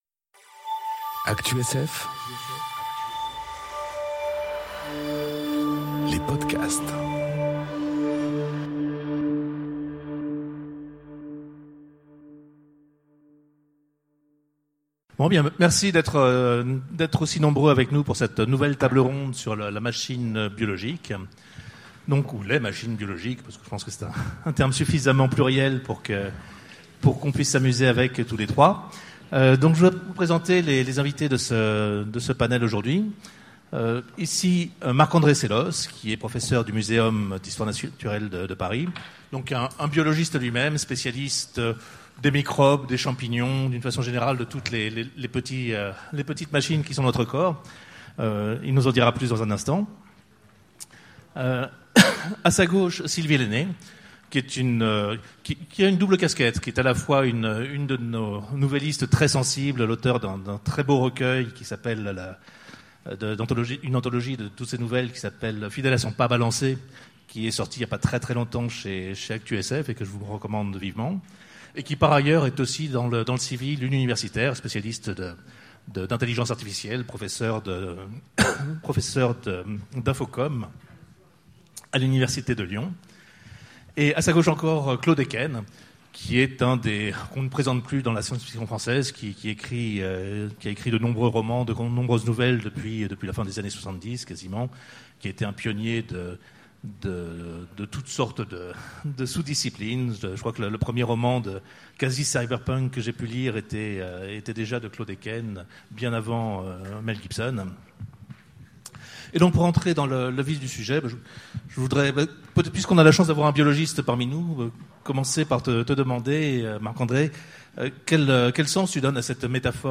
Conférence La machine biologique enregistrée aux Utopiales 2018